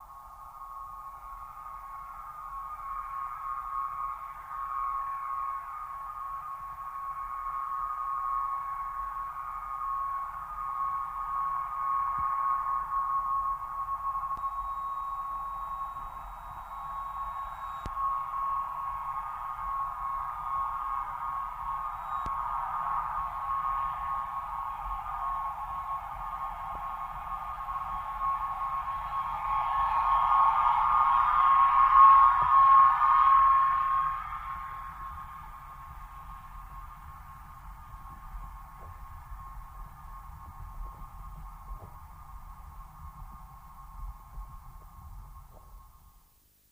Torpedos
Torpedo Jet By